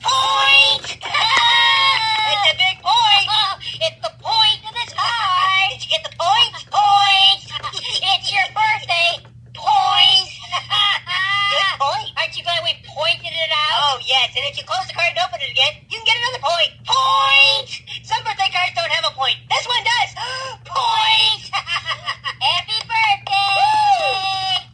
One Big Point! is a hoops&yoyo Pop-Up greeting card with sound made for birthdays.
Card sound